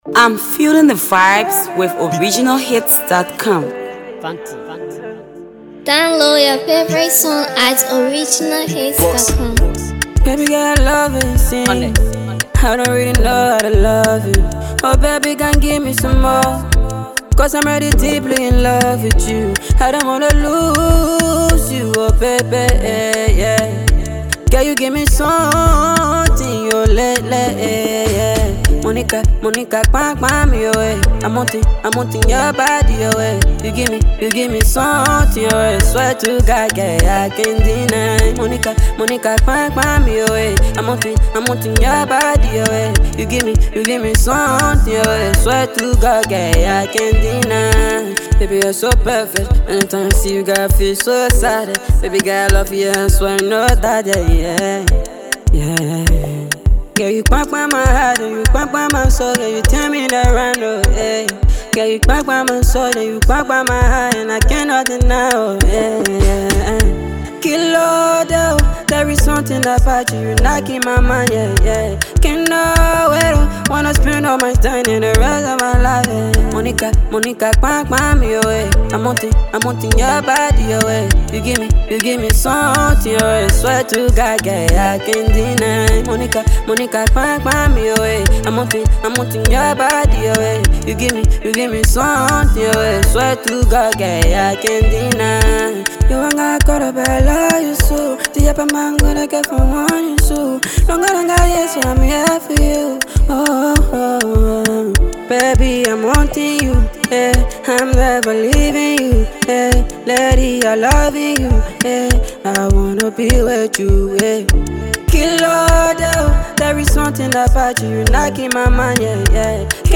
Afro Pop
comes with a mid-tempo instrumental